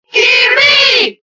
Category:Crowd cheers (SSBB) You cannot overwrite this file.
Kirby_Cheer_Italian_SSBB.ogg